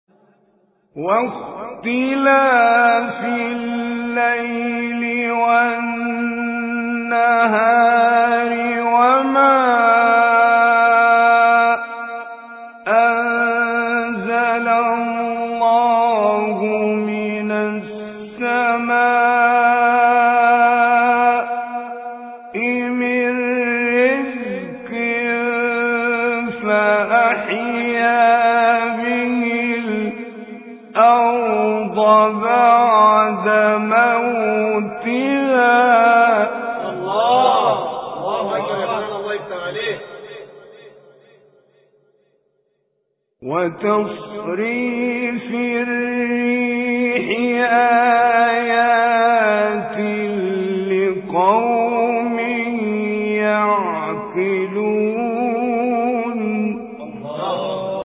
تلاوت سوره جاثیه با صوت «حلمی الجمل»
گروه شبکه اجتماعی ــ تلاوت آیاتی از سوره‌ مبارکه جاثیه که شامل آیه‌های بهاریست، با صوت حلمی الجمل را می‌شنوید.
حلمی جمل آیات 1 تا 37 سوره مبارکه جاثیه را تلاوت کرده است.